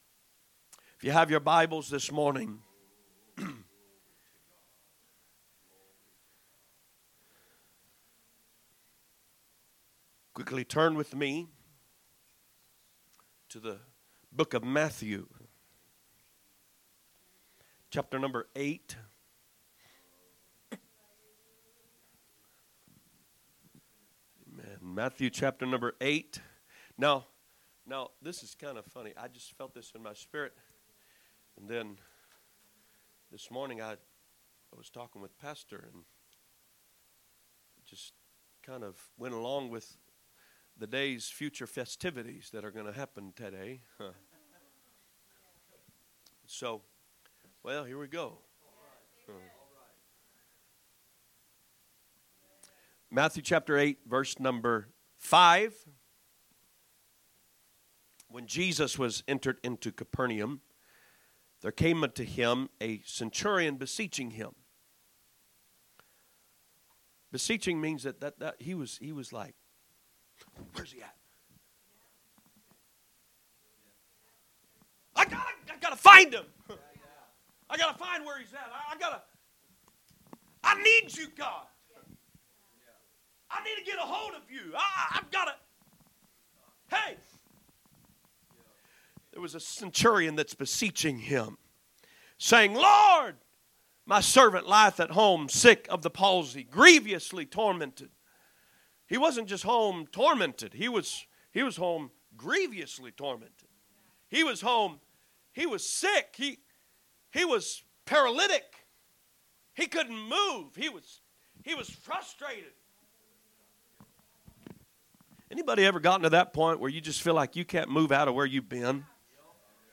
From Series: "Guest Speakers"
Sunday Morning Message - Revival